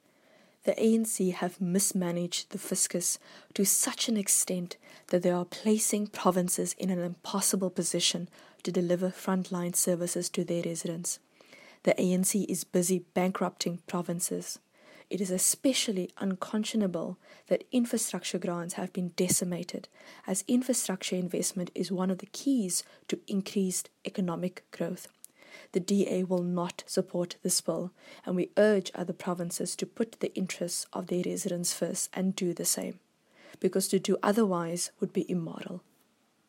English soundbite from MPP Deidré Baartman attached.